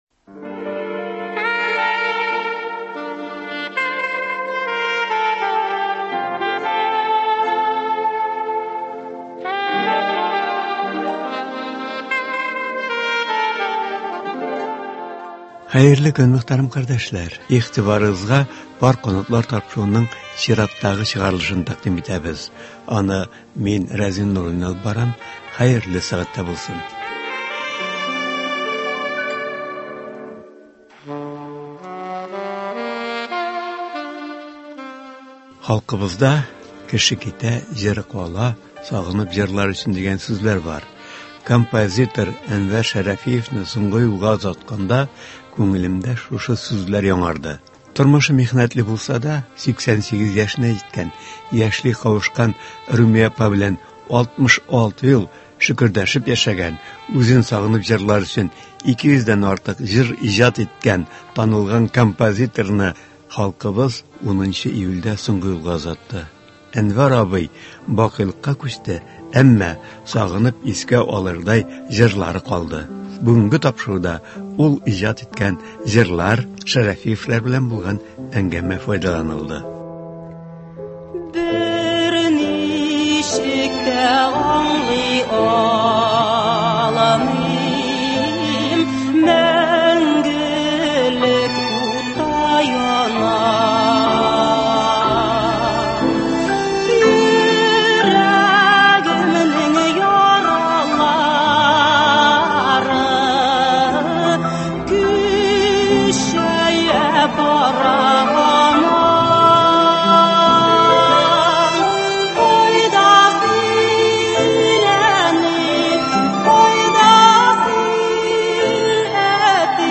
тапшыруда моннан берничә ел элек ясалган әңгәмә файдаланыла.